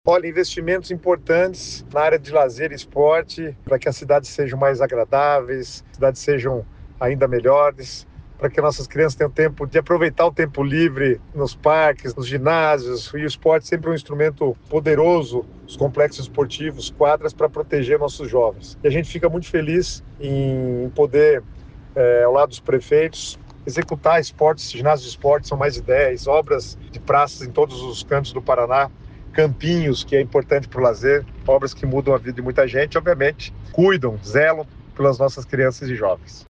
Sonora do secretário das Cidades, Guto Silva, sobre o investimento em obras de esporte e lazer em diversas regiões do Paraná